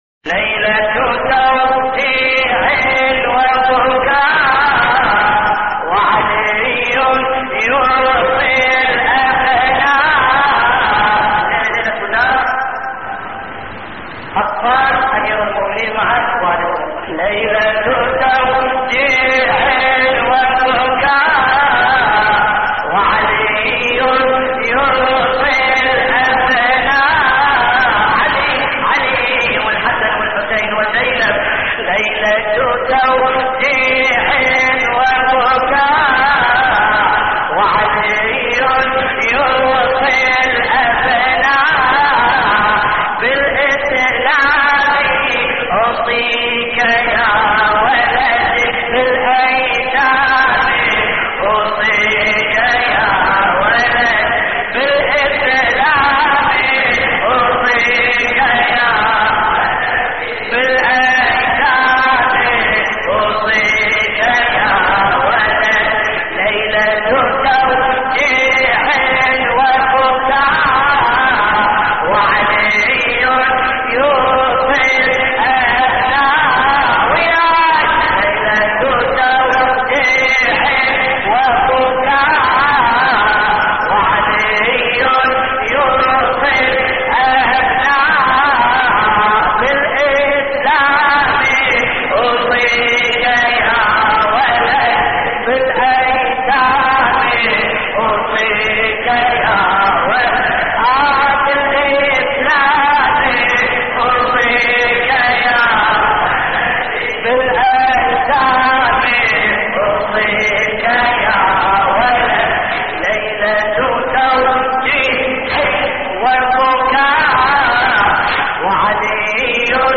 تحميل : ليلة توديع وبكاء وعلي يوصي الأبناء / الرادود باسم الكربلائي / اللطميات الحسينية / موقع يا حسين